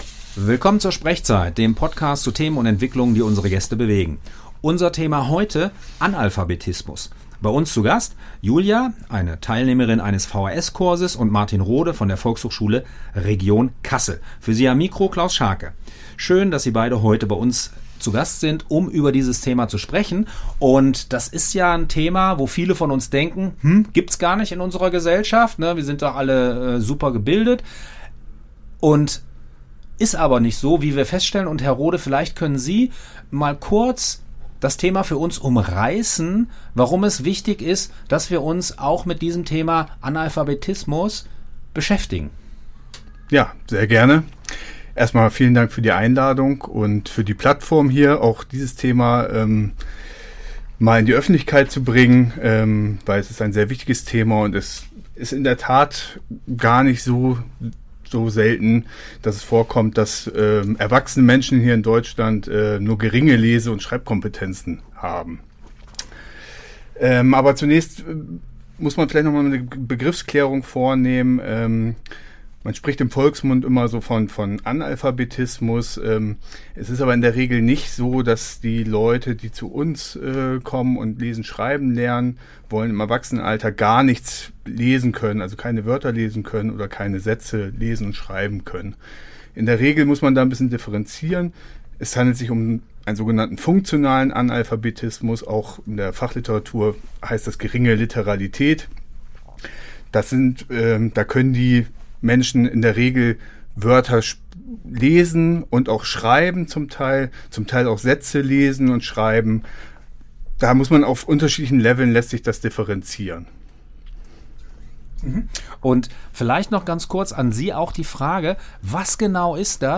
Diese Folge wurde am 29. September 2025 beim Freien Radio Kassel aufgezeichnet.